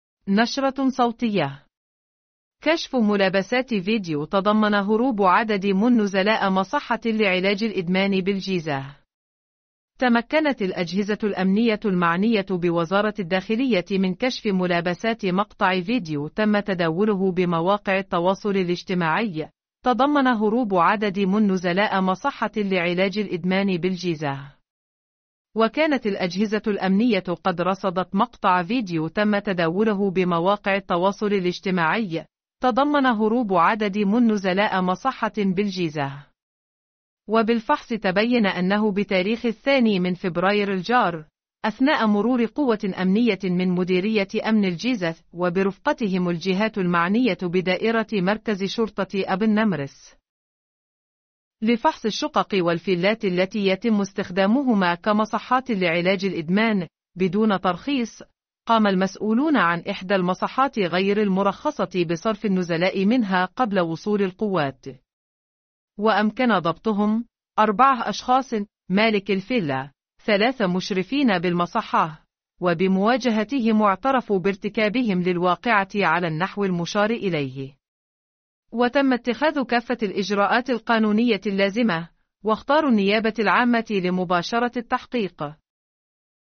نشرة صوتية..